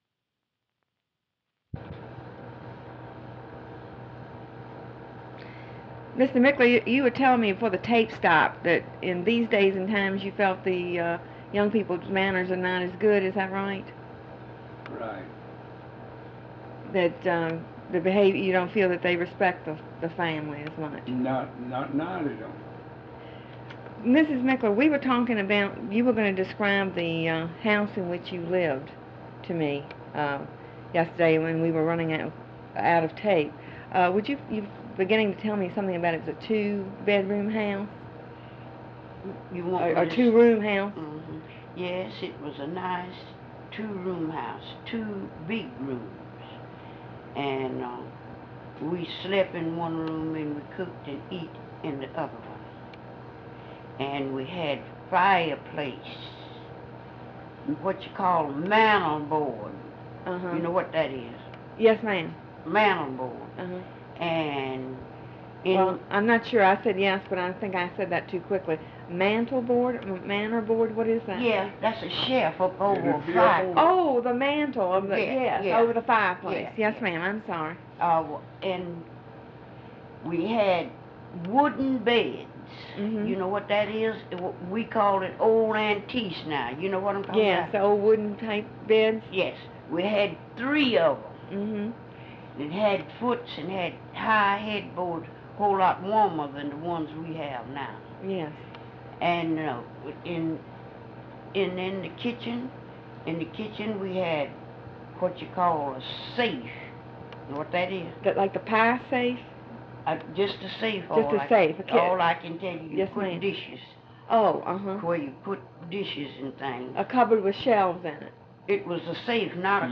Part of Interview